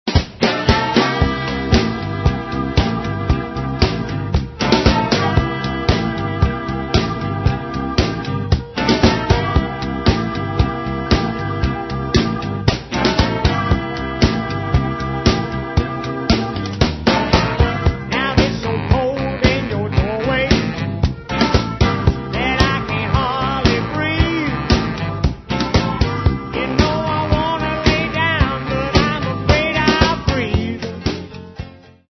historical country rock, mixed with gospel and blues